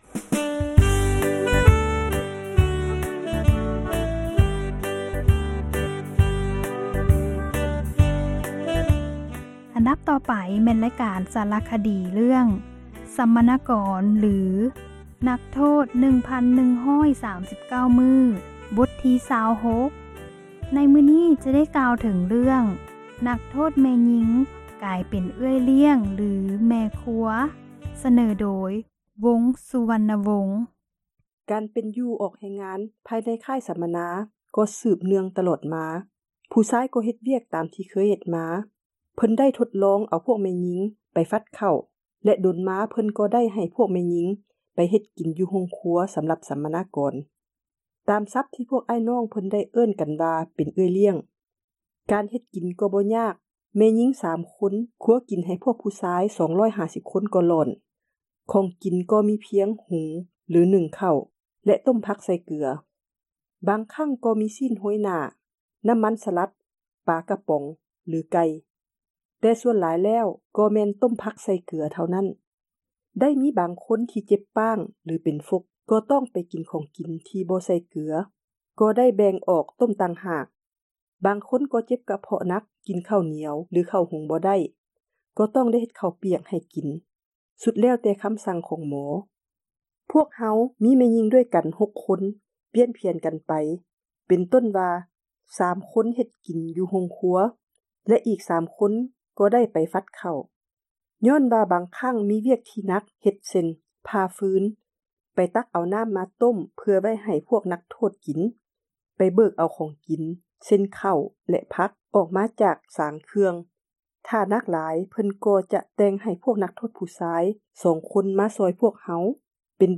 ສາຣະຄະດີ ເຣື້ອງ ສັມມະນາກອນ ຫຼື ນັກໂທດ 1139 ມື້. ບົດ ທີ 26, ໃນມື້ນີ້ ຈະກ່າວເຖິງ ນັກໂທດ ແມ່ຍິງ ກາຍເປັນ ເອື້ອຍລ້ຽງ ຫລື ແມ່ຄົວ. ສເນີໂດຍ